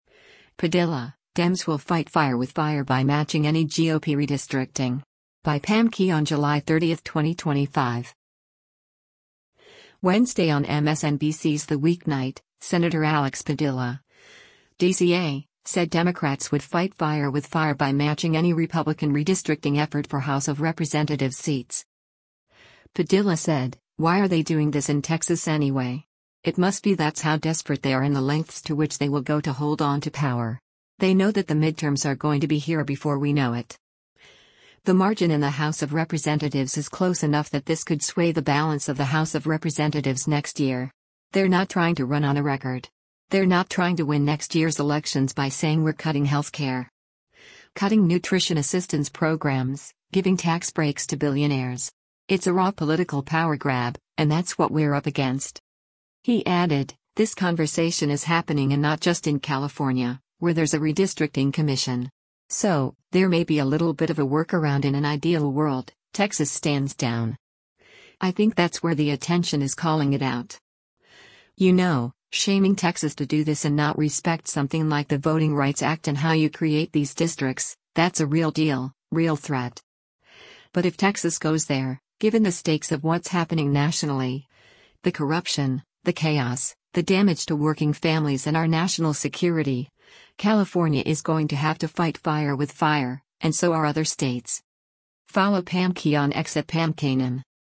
Wednesday on MSNBC’s “The Weeknight,” Sen. Alex Padilla (D-CA) said Democrats would “fight fire with fire” by matching any Republican redistricting effort for House of Representatives seats.